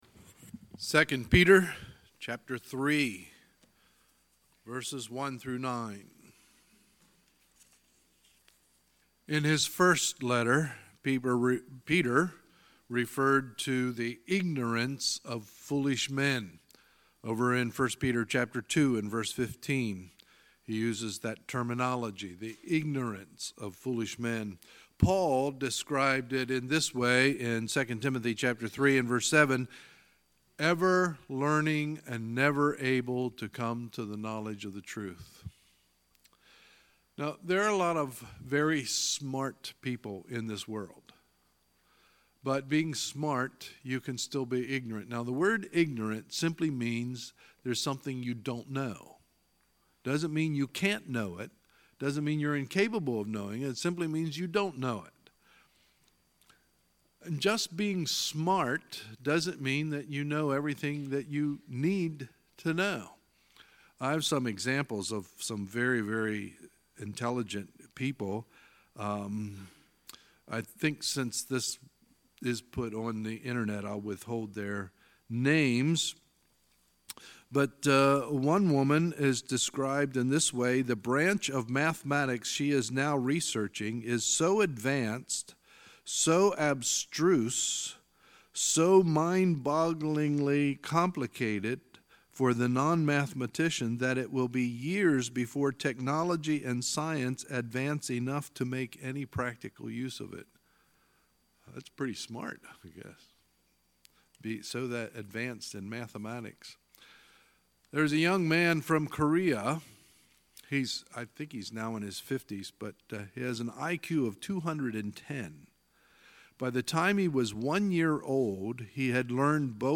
Sunday, November 4, 2018 – Sunday Morning Service